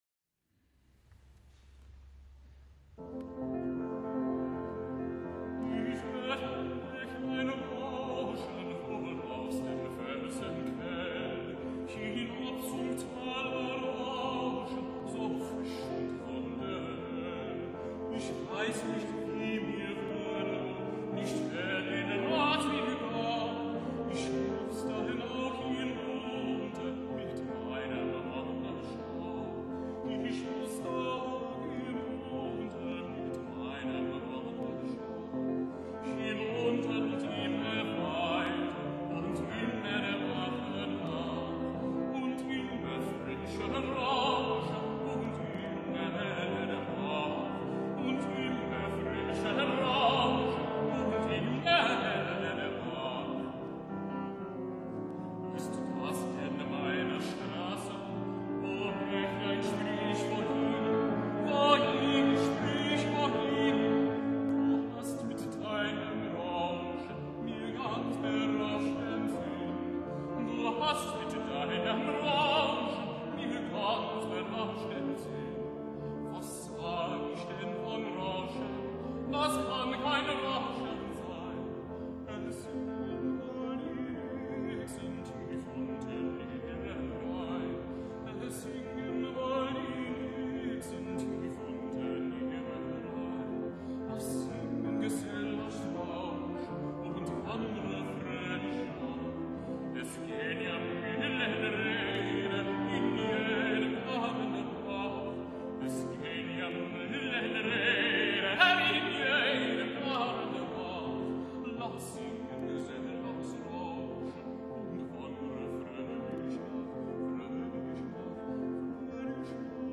Vocal Solo
Schubert: Wohin?, recorded live